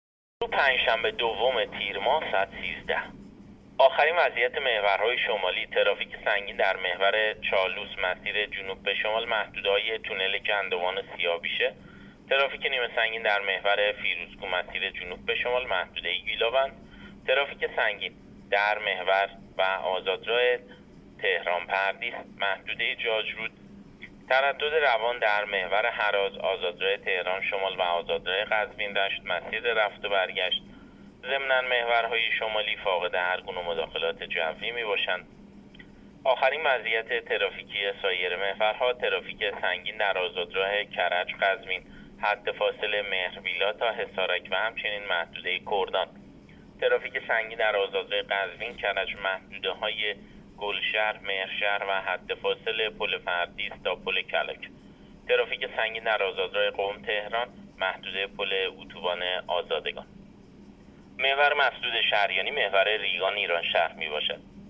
گزارش رادیو اینترنتی از آخرین وضعیت ترافیکی جاده‌ها تا ساعت ۱۳ دوم تیر؛